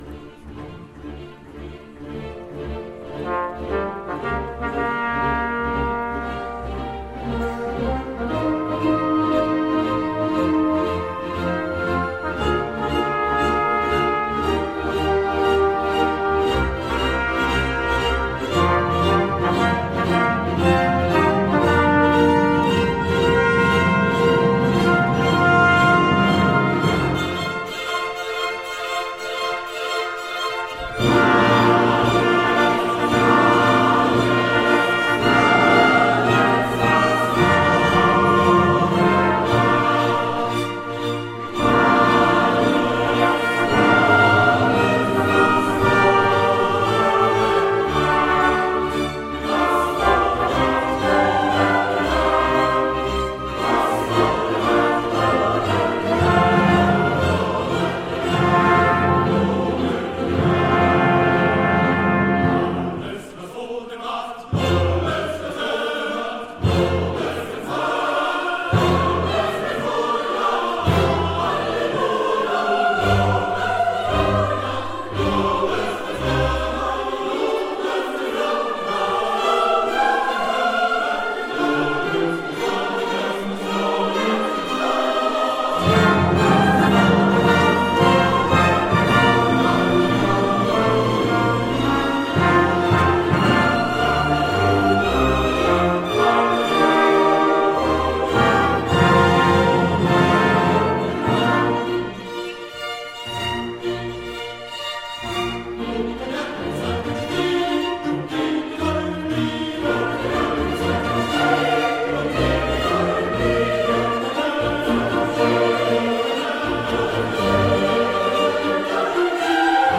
Hören Sie sich einige Stücke an, gesungen von der Kantorei und dem JuLifa-Chor (Junge Lieder für alle) der Pfarrei Herz Jesu oder gespielt an der Schuke-Orgel der Stadtpfarrkirche!
Die Kantorei Bad Kissingen und die Meininger Hofkapelle mit Felix Mendelssohn Bartholdys Symphonie Nr. 2 „Der Lobgesang“:
Sopransolo-geschnitten.mp3